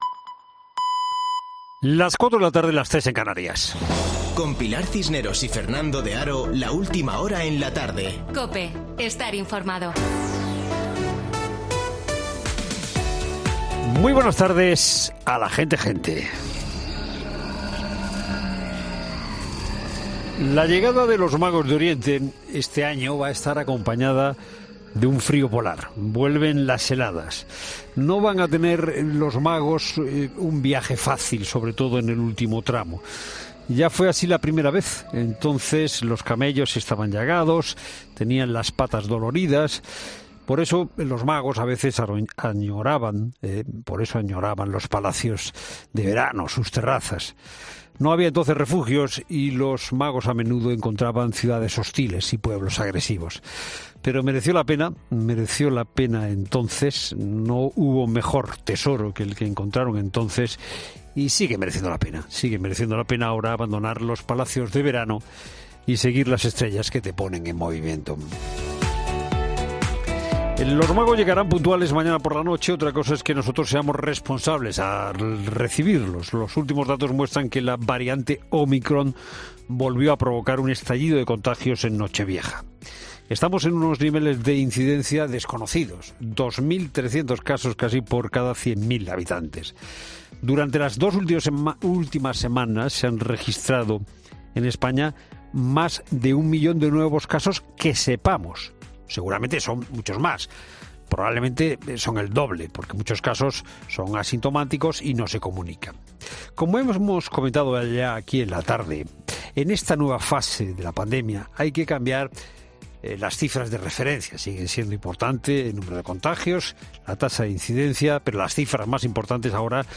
Boletín de noticias COPE del 4 de enero de 2022 a las 16:00 horas